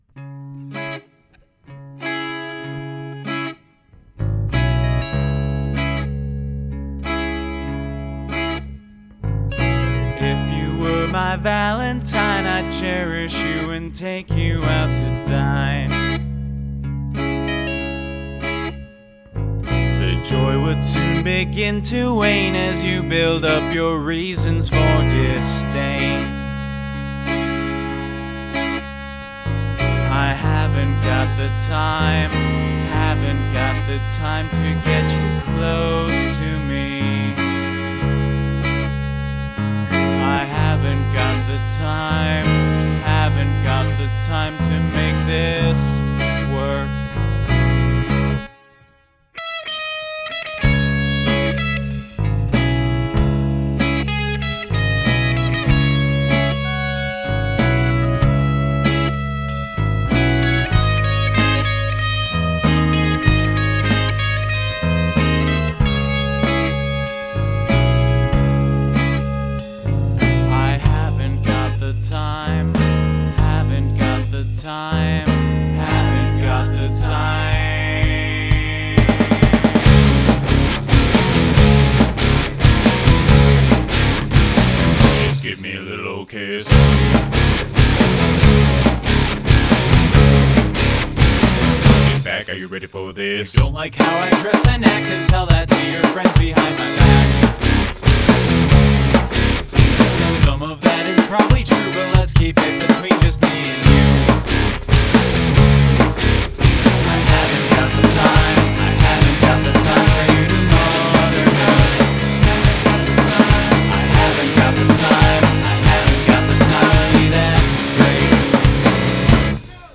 Dramatic Change in Tempo